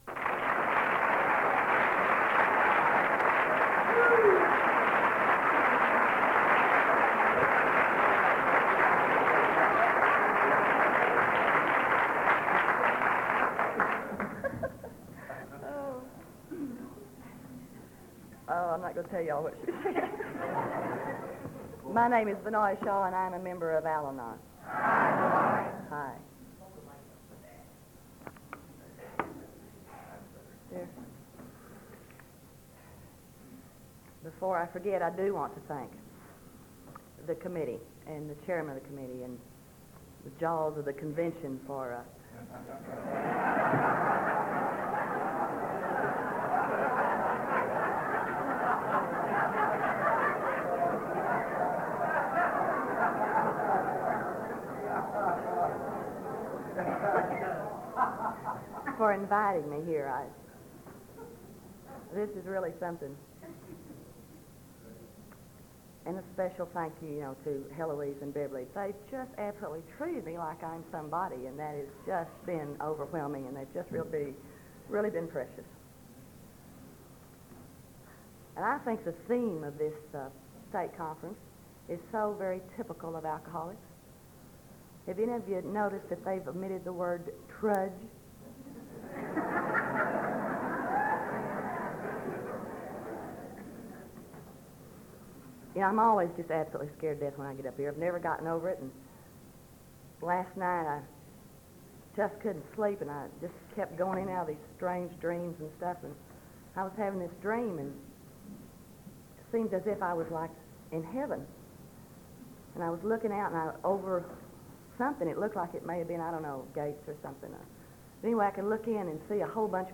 37th Texas State Convention &#8211